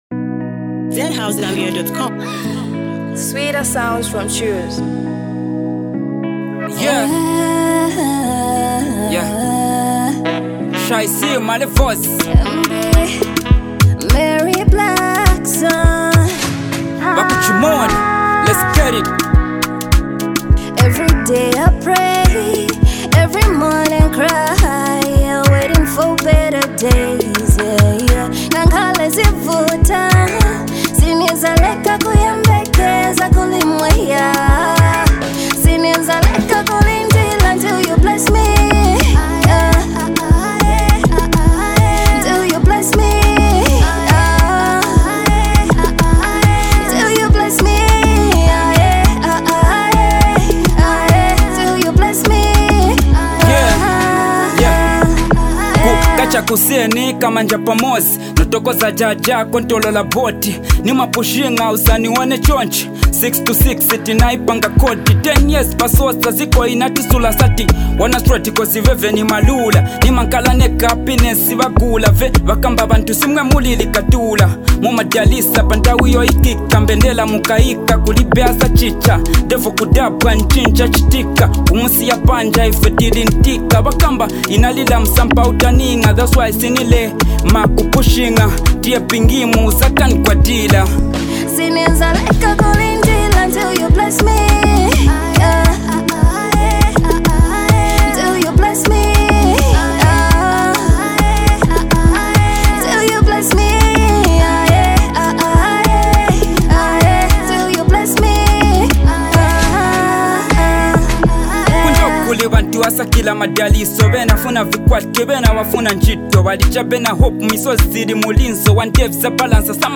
soulful voice